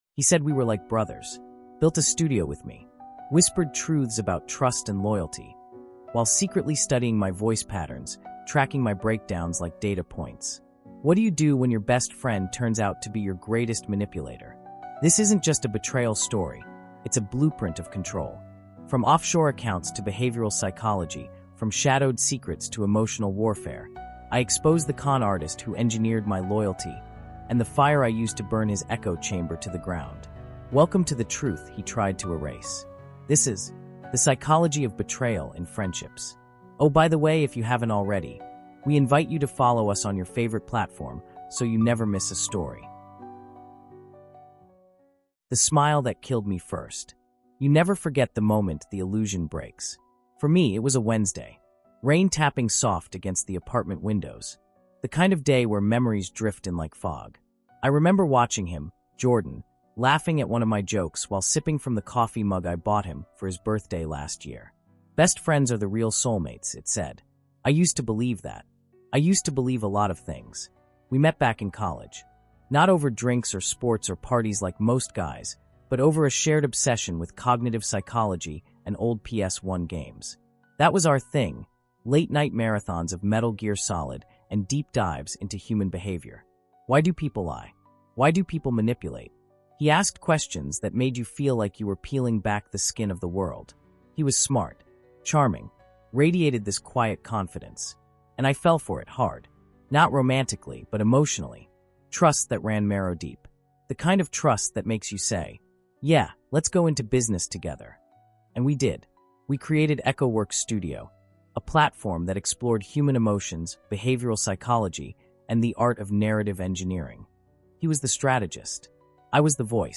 The Psychology of Betrayal in Friendships is a gripping true crime and dark fiction audio series that exposes the chilling reality of trust turned weapon. Told in six suspense-driven chapters, this first-person thriller explores the twisted tactics of a man who used behavioral psychology, marketing strategies, communication techniques, and NLP to conduct a long con hidden behind mentorship.